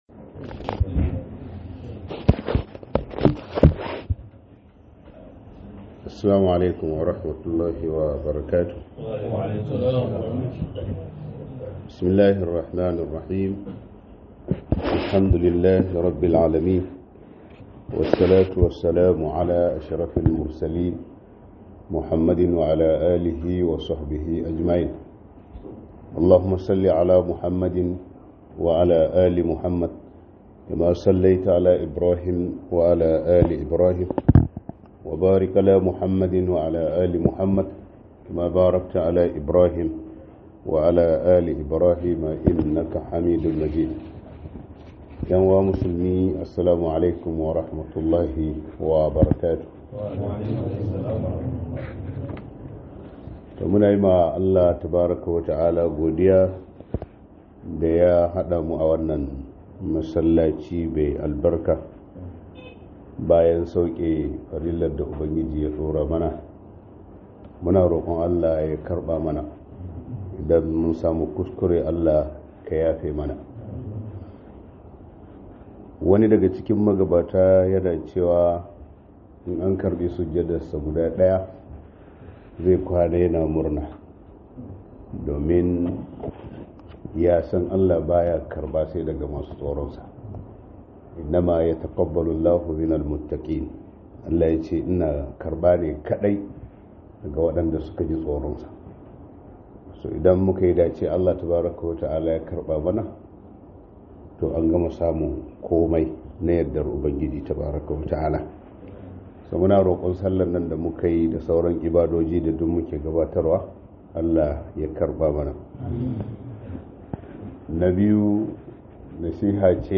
Mafitar Damuwoyin da Muke Ciki - MUHADARA